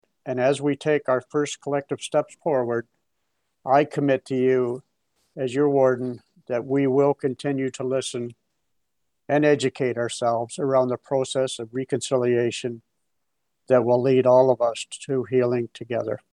Hastings County Council marked the National Day for Truth and Reconciliation at its meeting on Thursday.